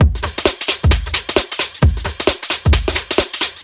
Index of /musicradar/uk-garage-samples/132bpm Lines n Loops/Beats
GA_BeatDMod132-02.wav